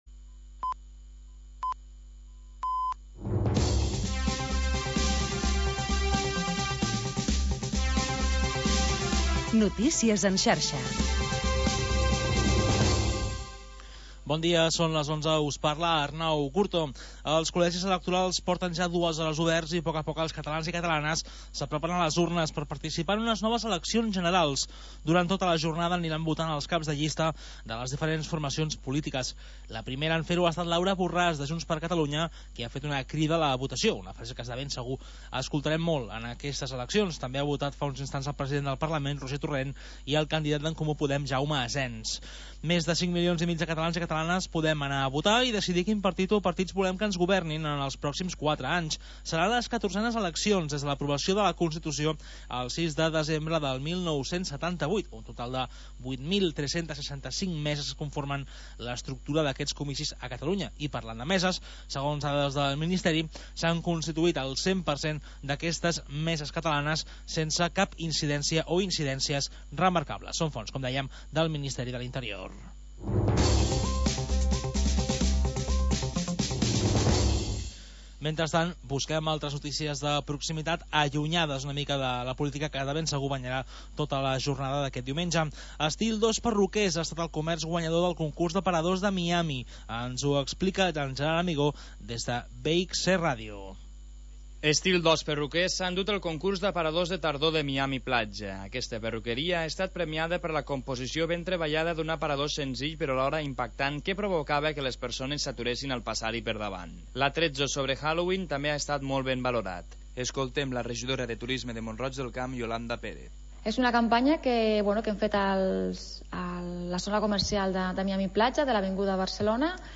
Havanera, cant de taverna i cançó marinera